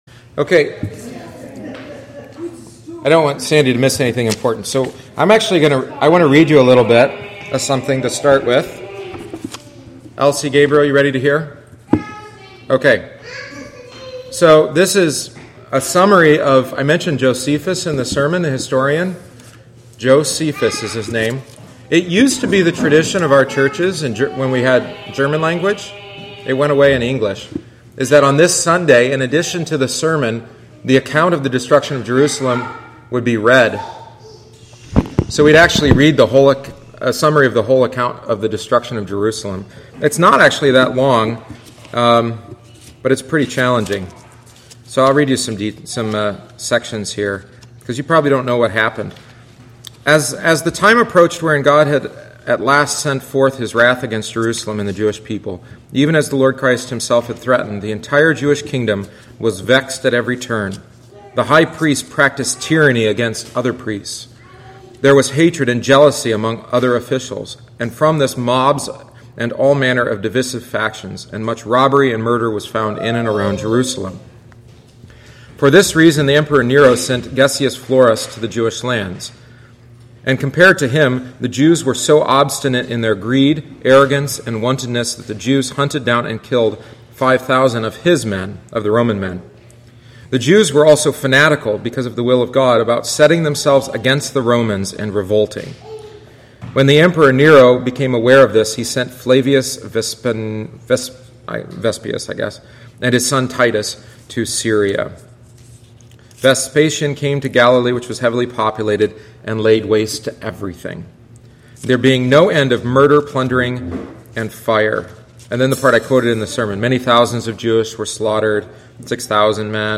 Bible Study on the Destruction of Jerusalem